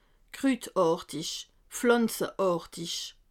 Strasbourg